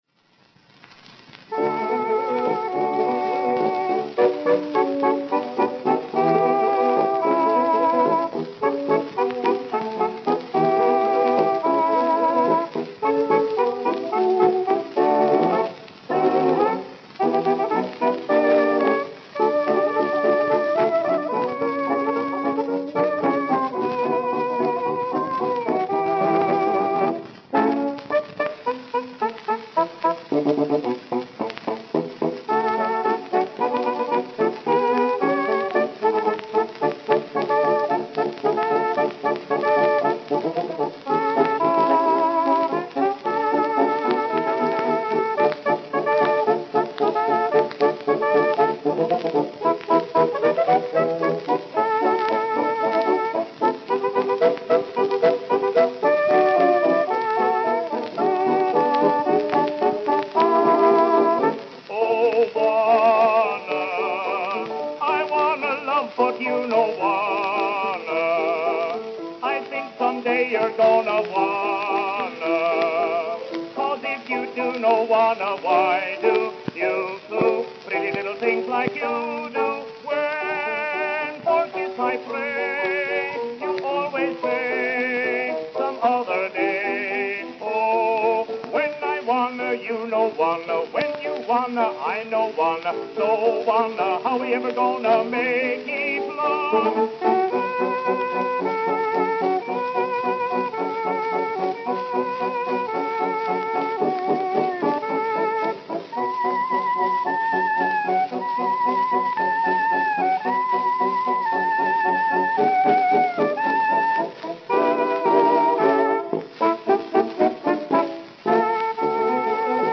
Vocal Chorus